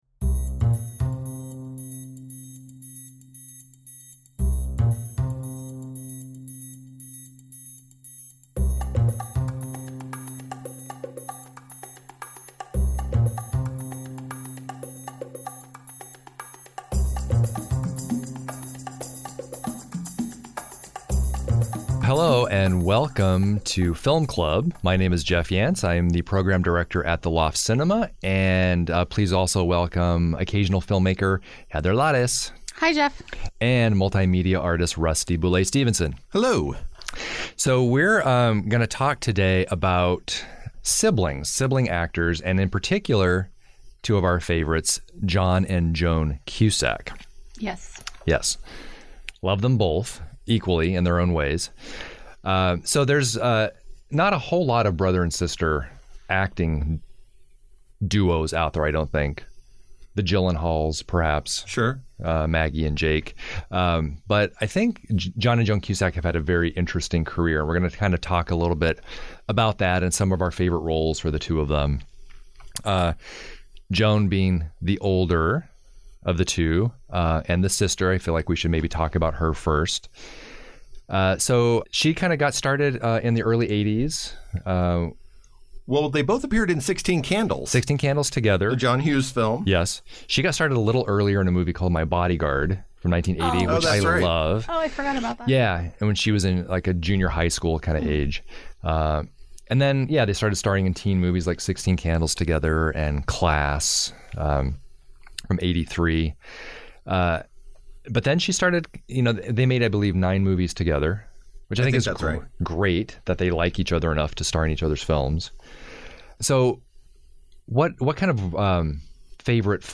Join the crew from Film Club as we discuss the careers of the Cusacks, Joan and John.